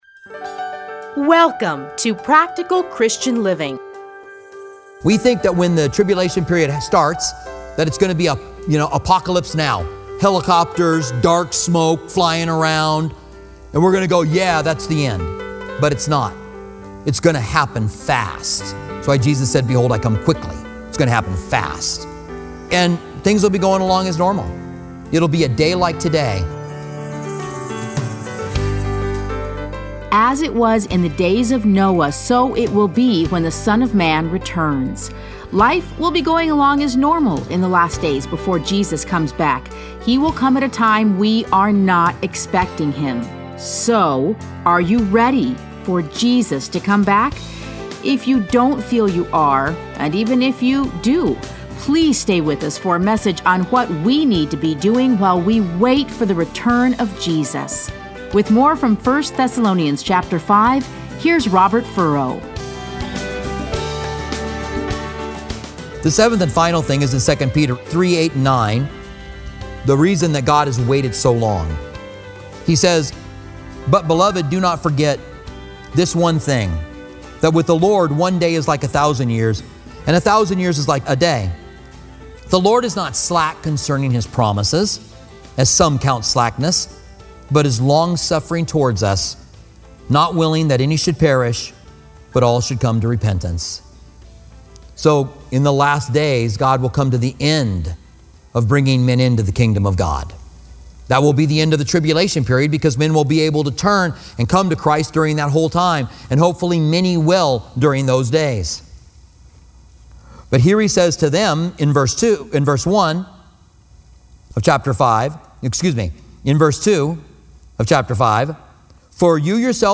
Listen to a teaching from 1 Thessalonians 5:1-28.